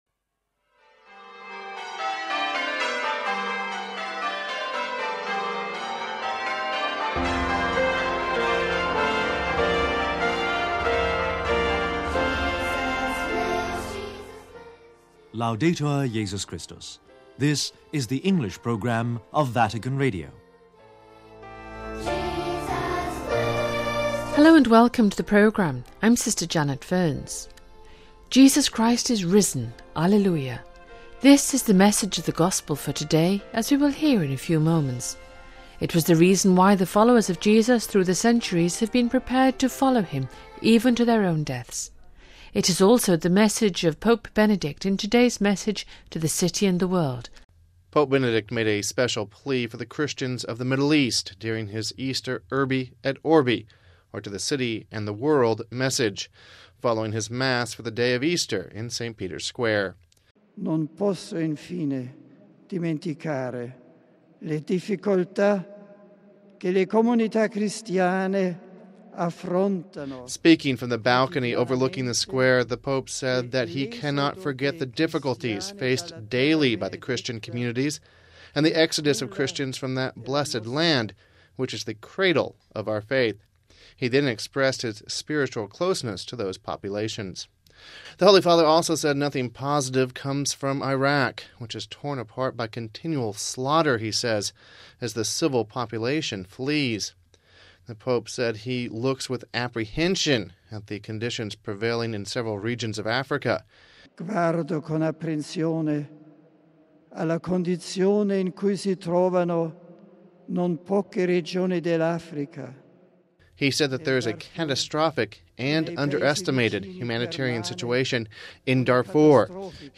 URBI ET ORBI - Pope Benedict XVI delivers his Easter Message to the City and the World. THE TRIUMPH OF EASTER - Reflections on the Sunday Gospel and the origins of the word Easter...